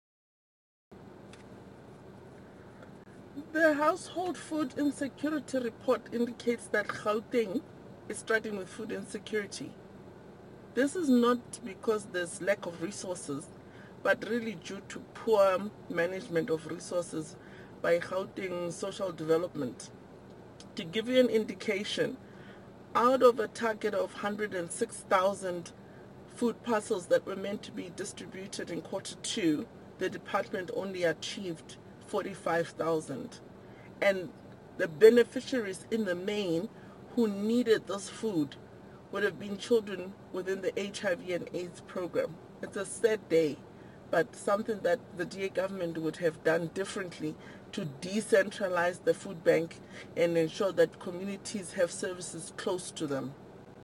soundbite by Refiloe Nt’sekhe MPL.